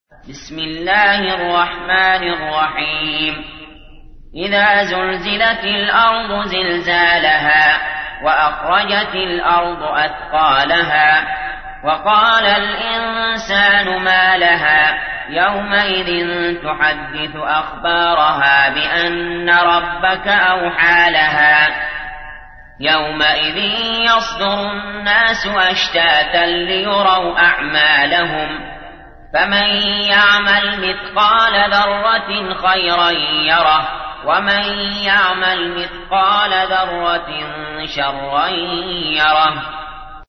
تحميل : 99. سورة الزلزلة / القارئ علي جابر / القرآن الكريم / موقع يا حسين